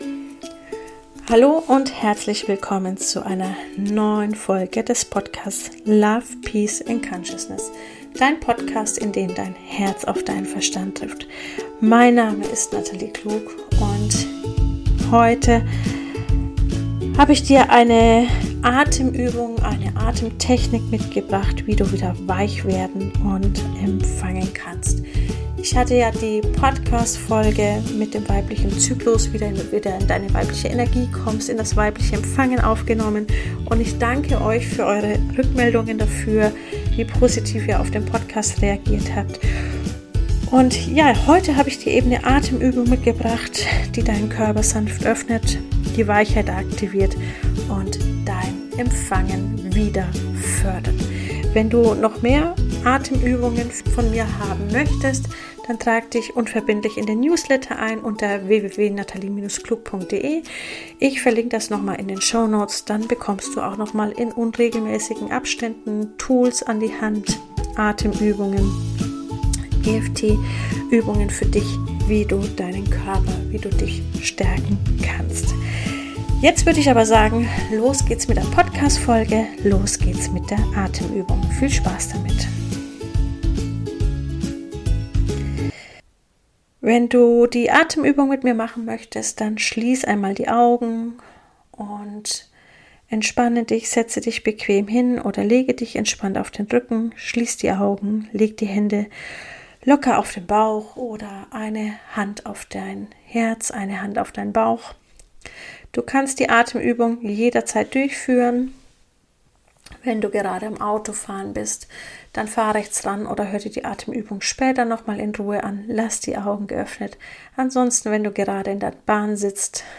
Weibliche Energie stärken: Atemübung für Weichheit, Vertrauen & innere Ruhe